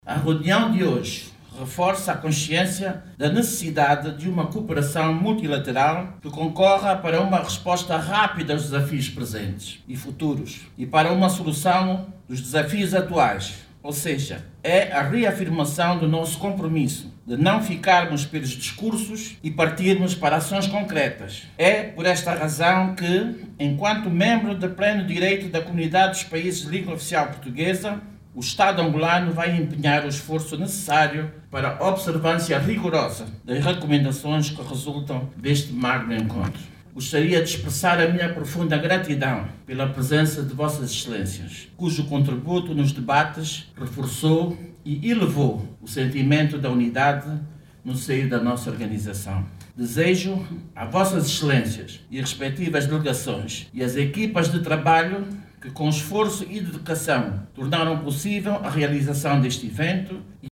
Oiça agora o aúdio da Ministra da Administração Pública, Trabalho e Segurança Social.
TERESA-DIAS-2.mp3